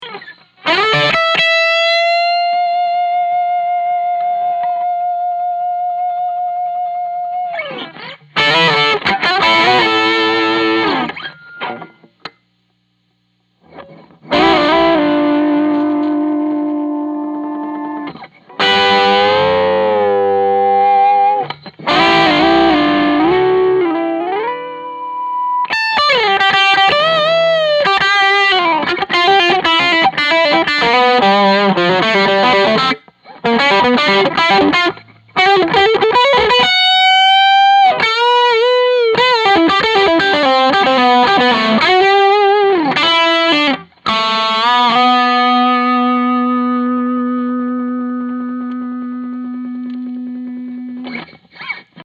We used a Two Rock 112, Open back cabinet with a Tone Tubby Alnico 16:
Gibson 335
335_OD_Solo_PAB_Some_Overtones
ODS_335_OD_Solo_PAB_Some_Overtones.mp3